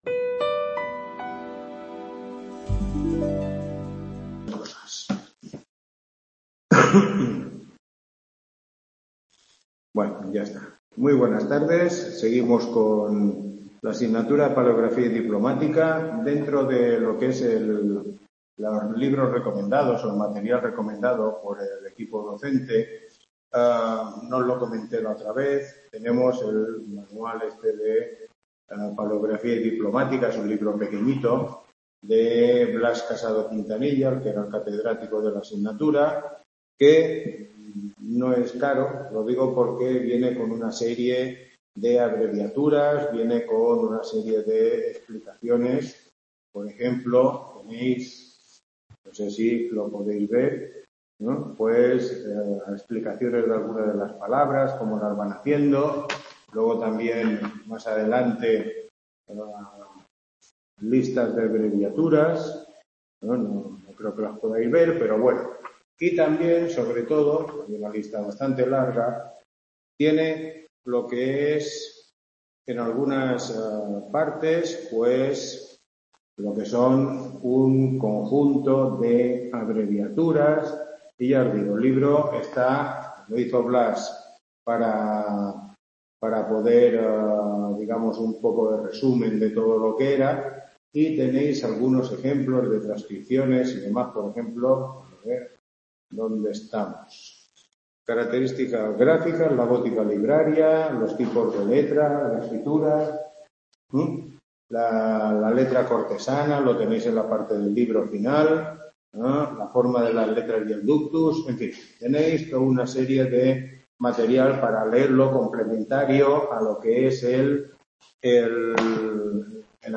Tutoría 03